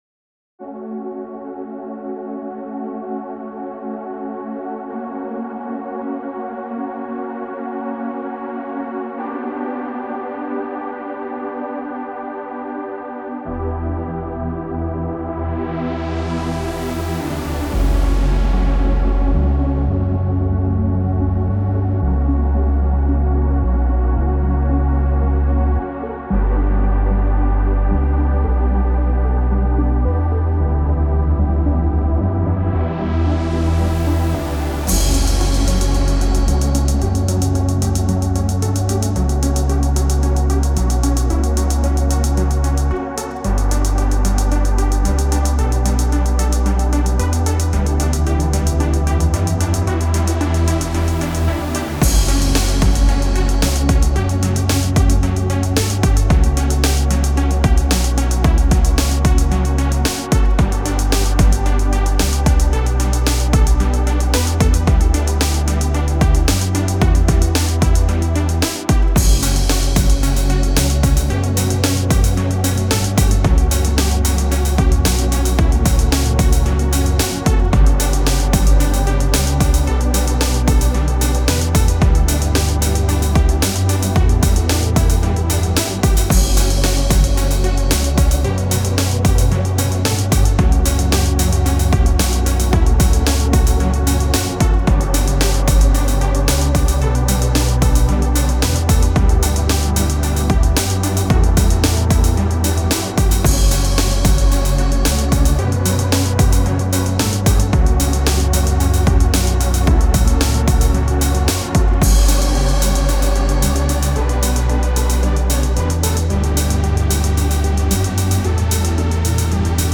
Incursión en el Future bass con tintes New age.